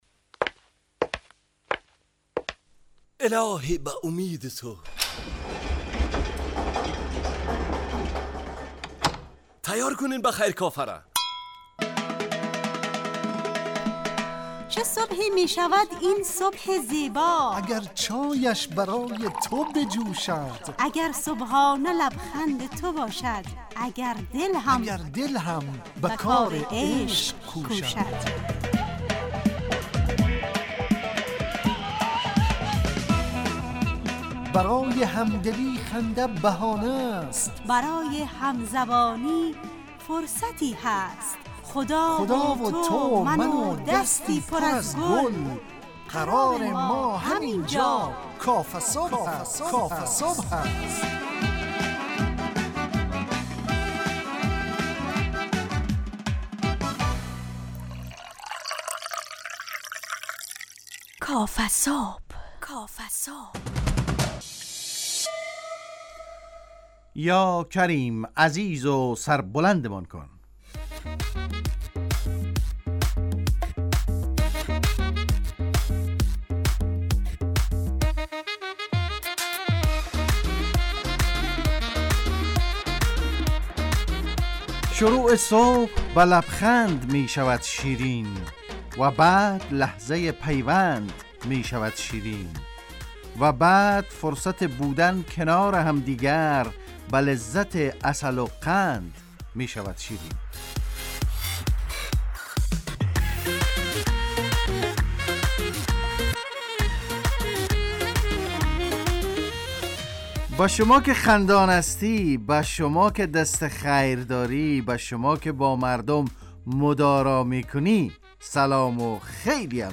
کافه صبح - مجله ی صبحگاهی رادیو دری با هدف ایجاد فضای شاد و پرنشاط صبحگاهی